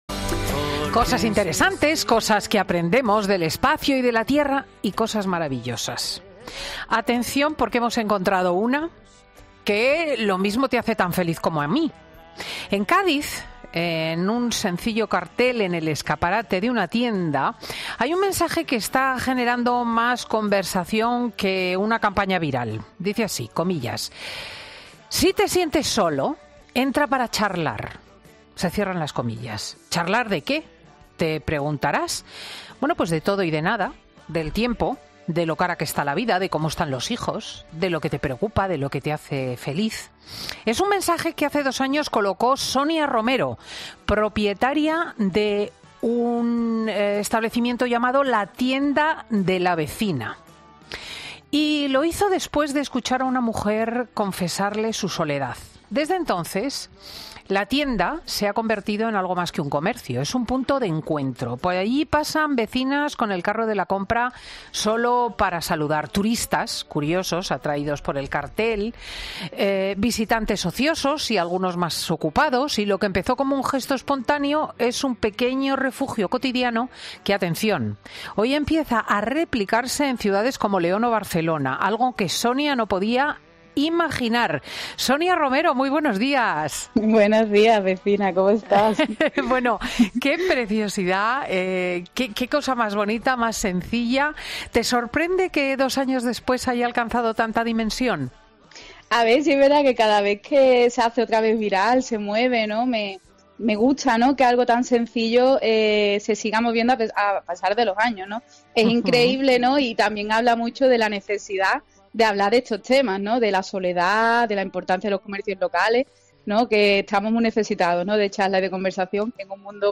quien ha compartido su historia en el programa ' Fin de Semana ' de COPE con Cristina López Schlichting .